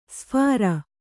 ♪ sphāra